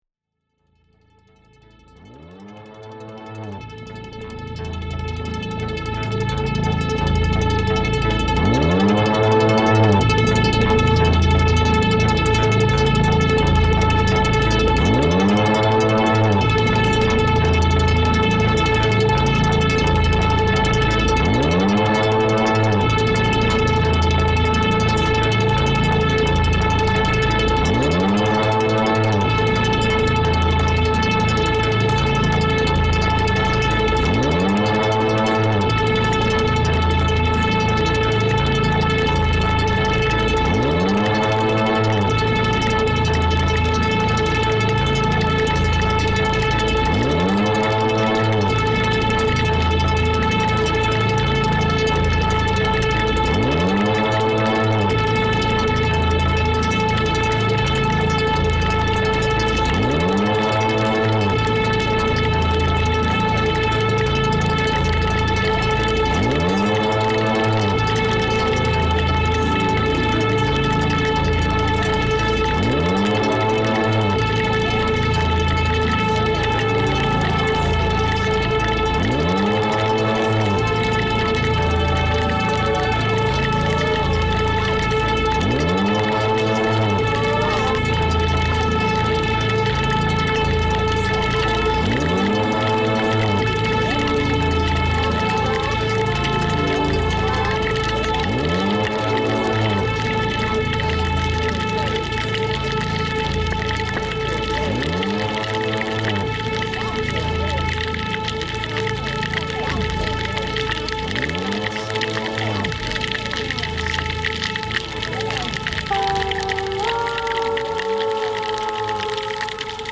Red Sea soundscape, Egypt reimagined